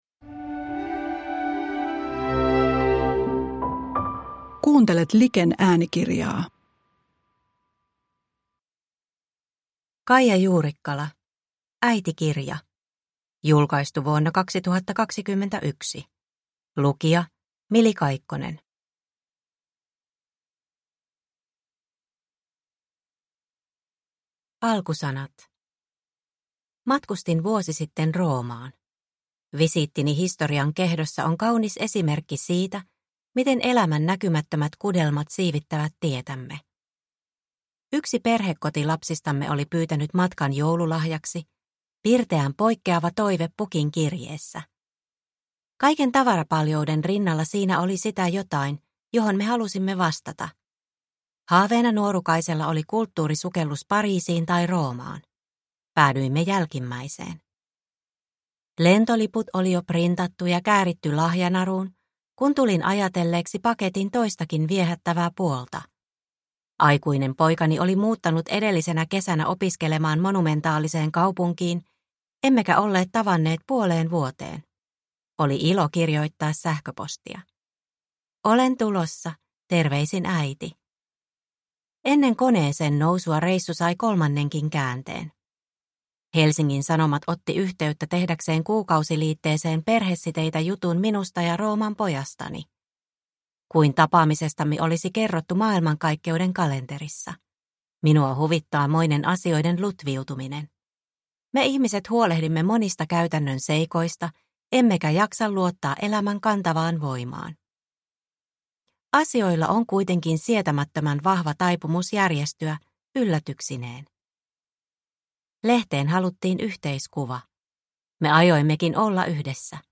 Äitikirja – Ljudbok – Laddas ner